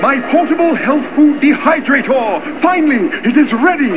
Note: All of these sounds are spoken by the cartoon characters, NOT the real guys, unless otherwise noted.
an NKOTB cartoon